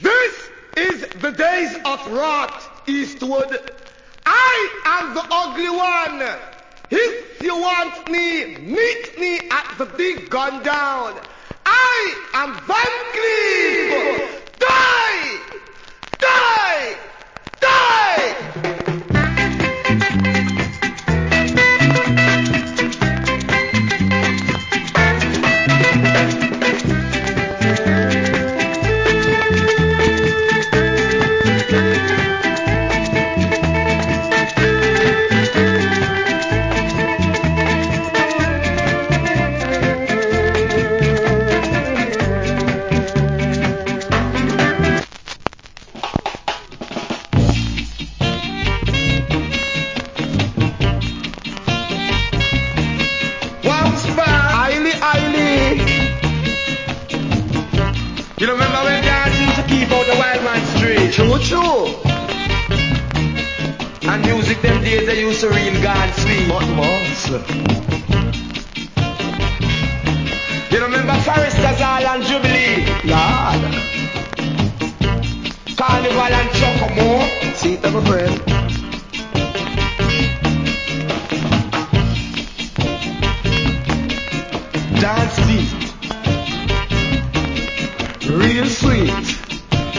Killer MC + Early Reggae Inst.